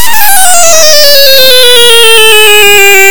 This sound effect is produced while the air supply in the current cavern is being drained before moving to the next cavern (see 37044).